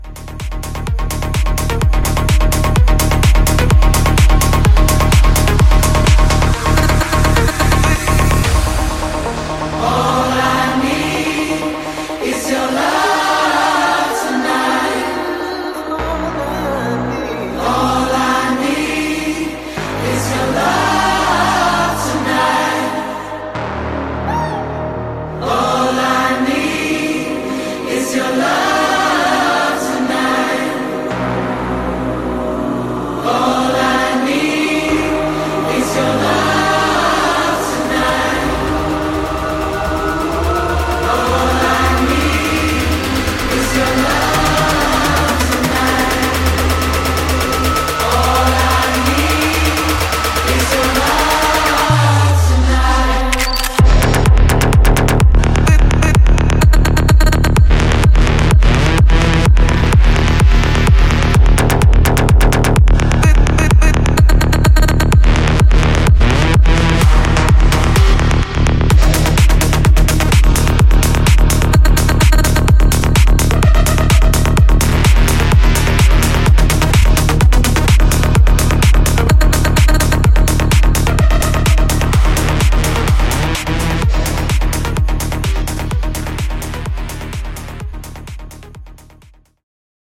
Tech House Mashup)Date Added